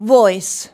VOICE.wav